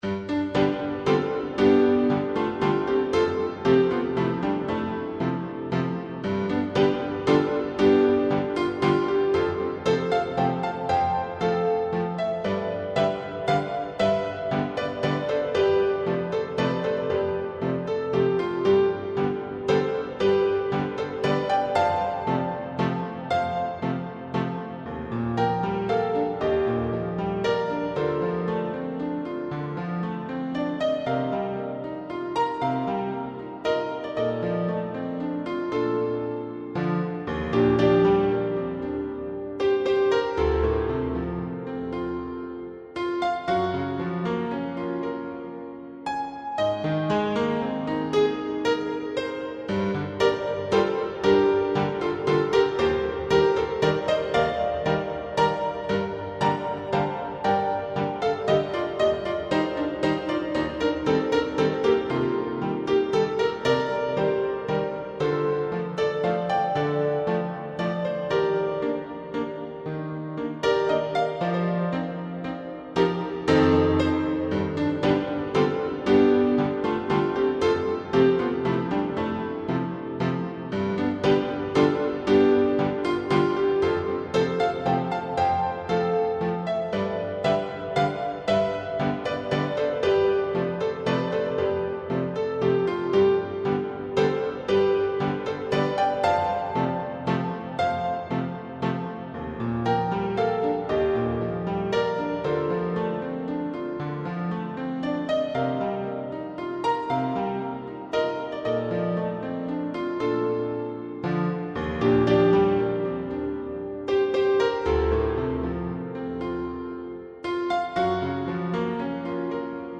BGM
スローテンポロング穏やか